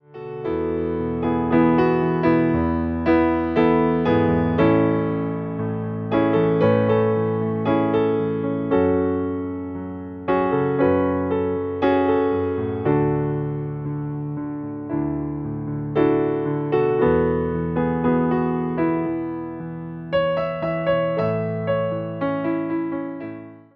Wersja demonstracyjna:
58 BPM
A – dur